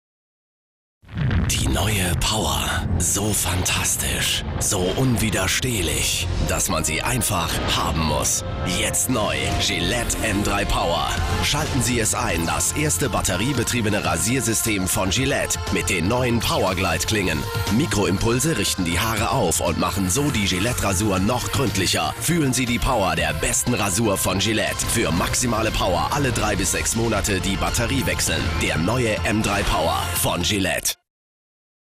dunkel, sonor, souverän, markant
Mittel plus (35-65)
Schwäbisch
Commercial (Werbung)